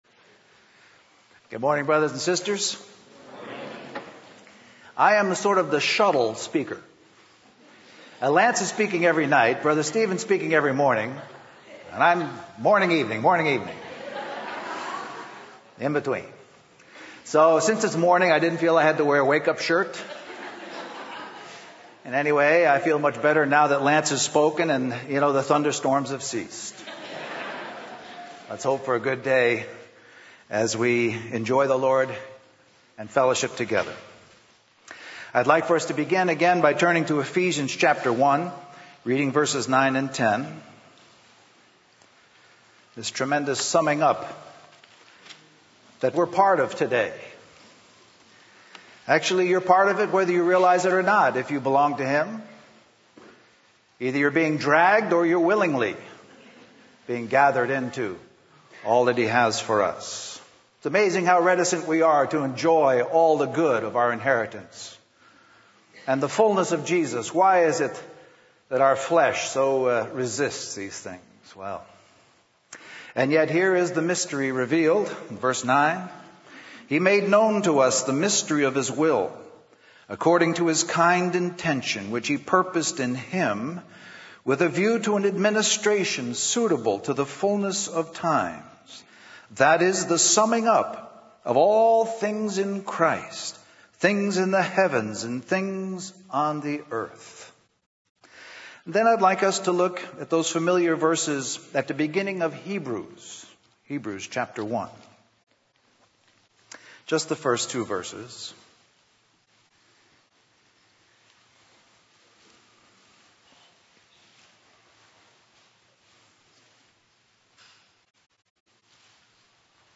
Christian Family Conference
Message